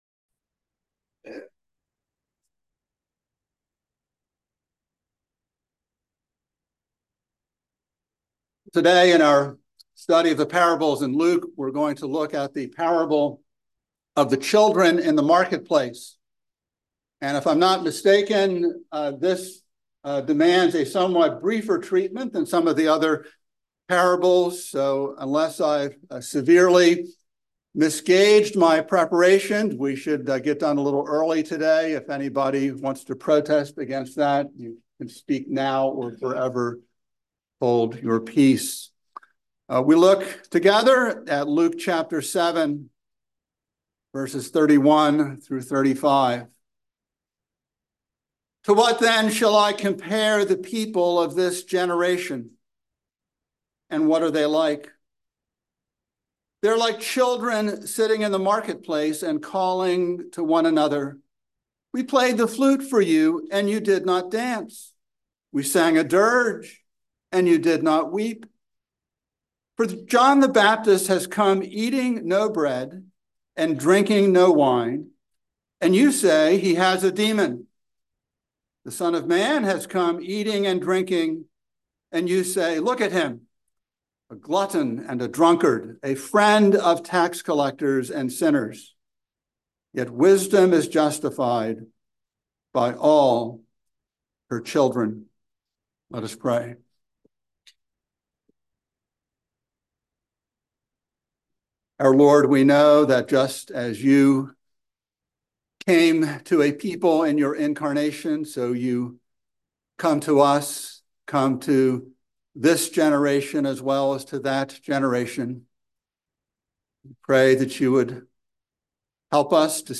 by Trinity Presbyterian Church | Feb 22, 2024 | Sermon